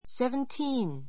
sevntíːn セ ヴ ン ティ ーン